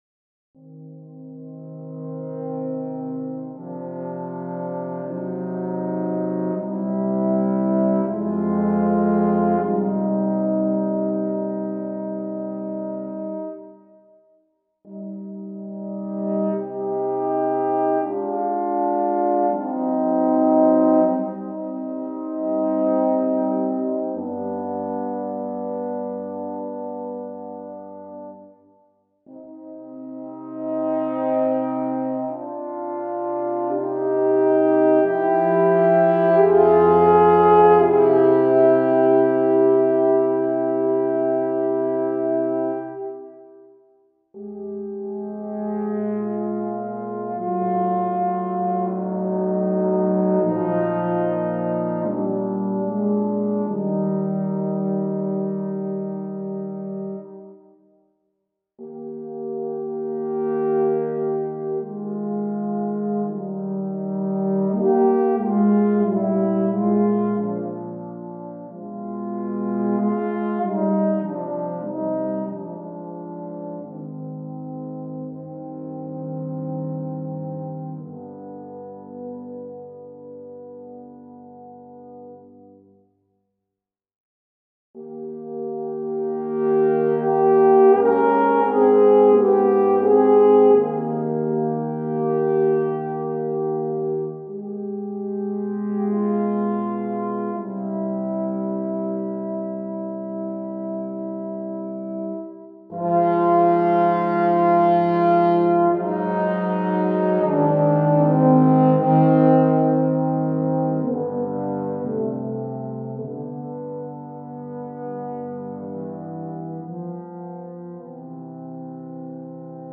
I don't have an alto, so it is two Tenors and one Bass.
It was not difficult to reposition them in the room, by using the Synhcron Player's mic pan controls, so that they could be centered instead of moved to the right.
Since I've morbid attitudes, I tried to tune (or detune…) them as real brass player would probably do.
There are a few intervals that I am revising, since they sound too dissonant.
Smoothed pitches, adjusting something by ear.